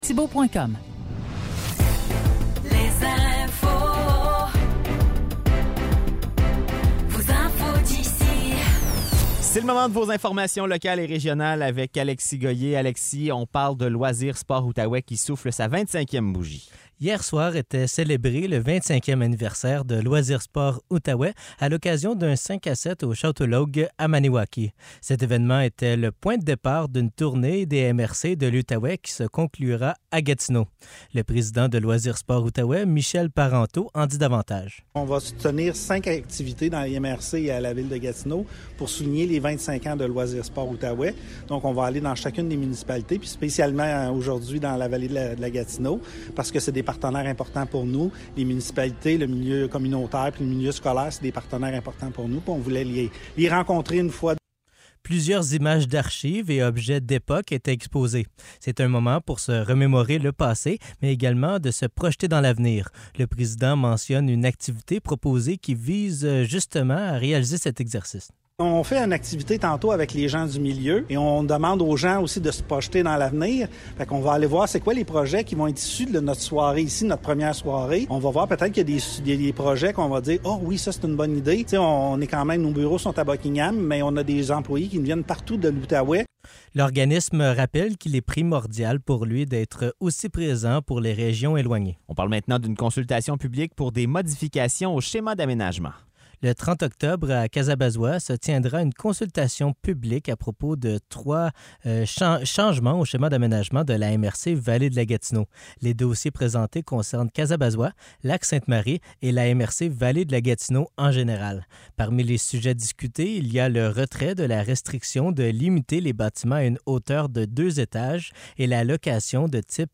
Nouvelles locales - 27 octobre 2023 - 16 h